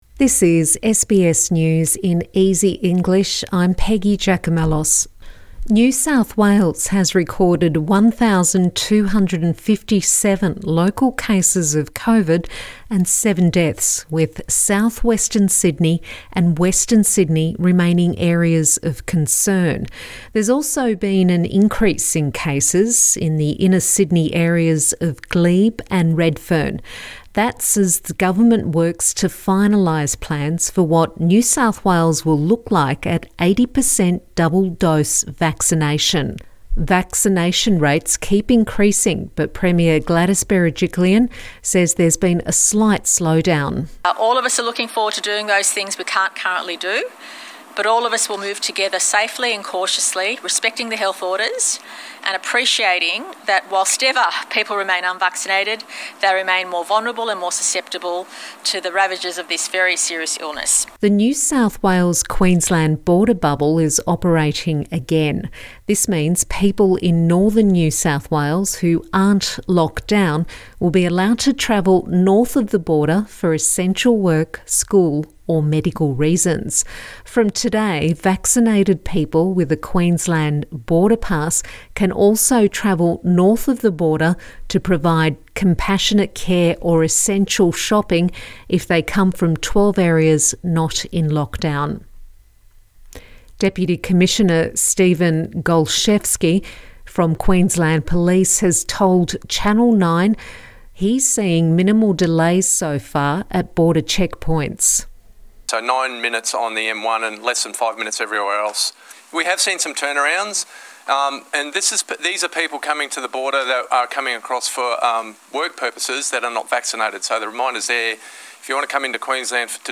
A daily 5 minute news wrap for English learners